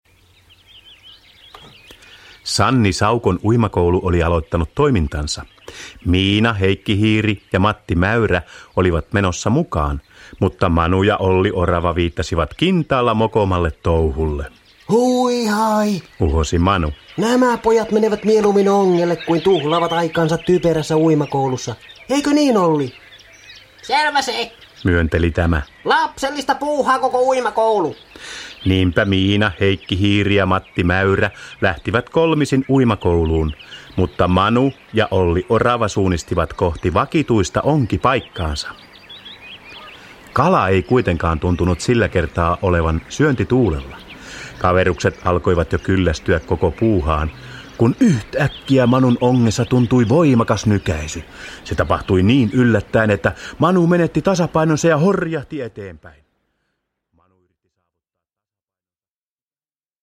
Miina ja Manu uimakoulussa – Ljudbok – Laddas ner